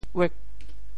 刖（跀） 部首拼音 部首 刂 总笔划 6 部外笔划 4 普通话 yuè 潮州发音 潮州 uêg8 文 潮阳 uag8 文 澄海 uag8 文 揭阳 uag8 文 饶平 uag8 文 汕头 uag8 文 中文解释 潮州 uêg8 文 对应普通话: yuè 古代的一種酷刑，把腳砍掉：“昔卞和獻寶，楚王～之”。